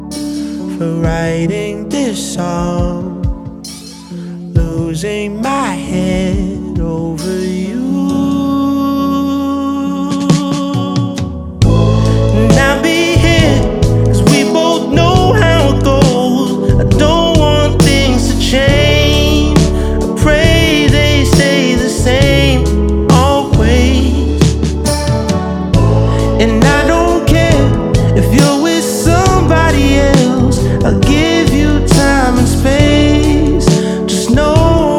Жанр: R&b / Соул